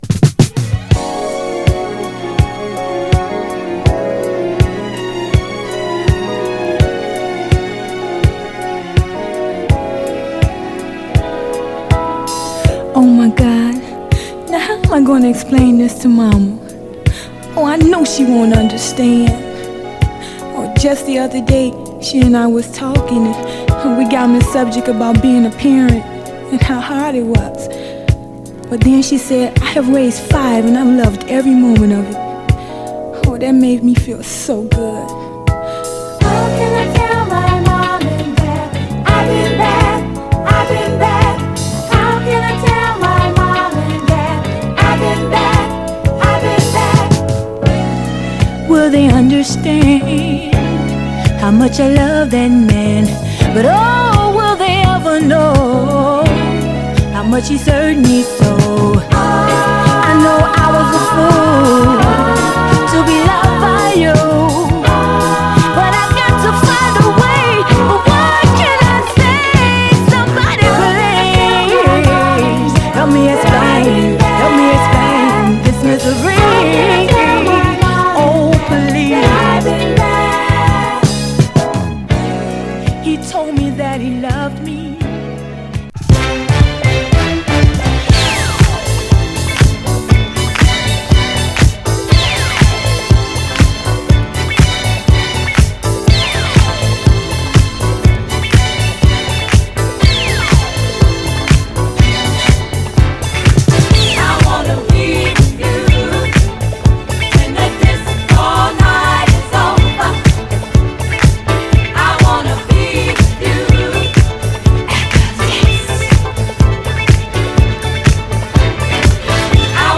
ガールズ・ディスコ・トリオ
盤はグロスがありキレイなコンディションですが、所々でプレス起因によるスチレンノイズが出る場合があります。
※試聴音源は実際にお送りする商品から録音したものです※